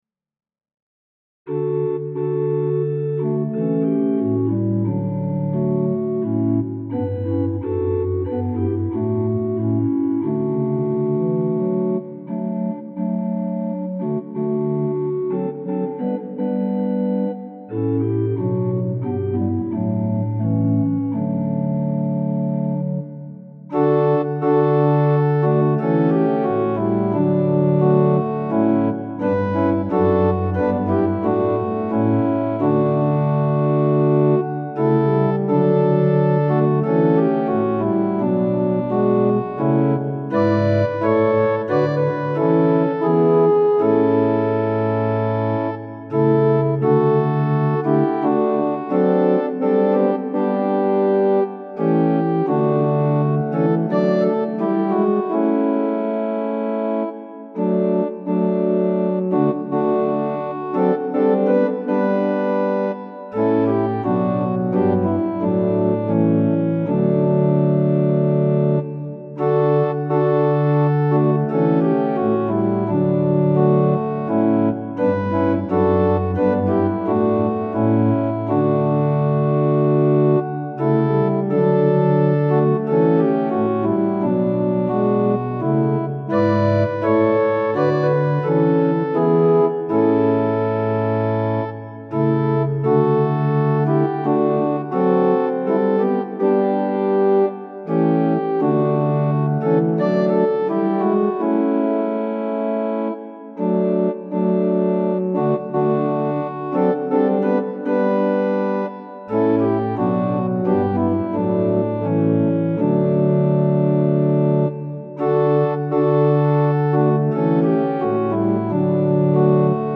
♪賛美用オルガン伴奏音源：
Tonality = D
Pitch = 440
Temperament = Equal